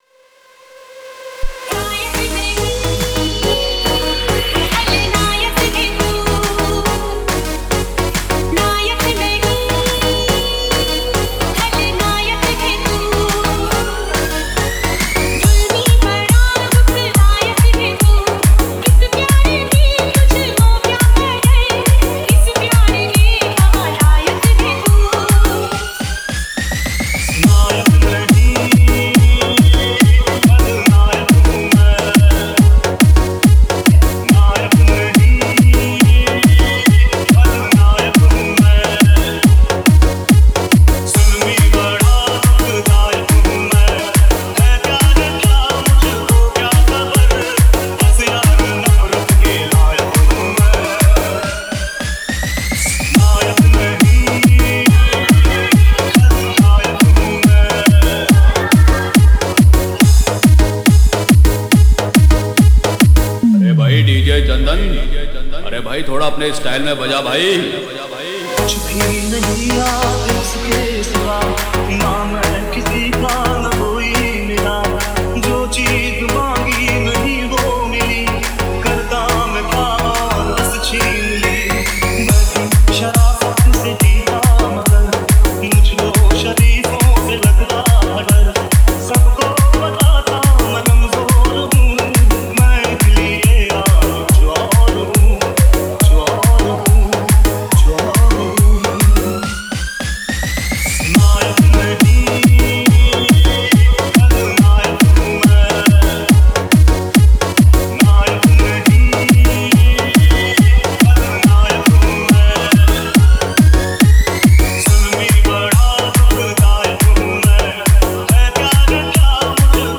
Hindi Dj Song